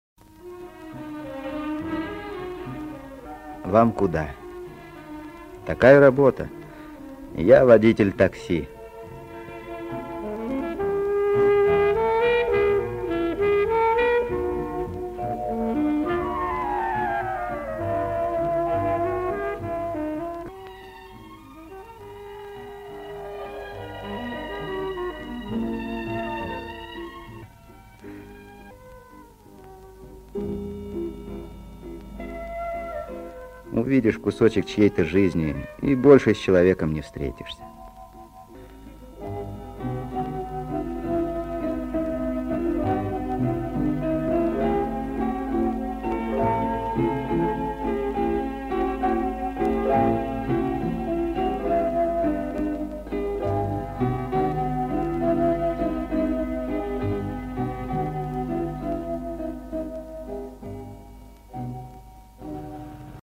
слышно по саксофону
гитаре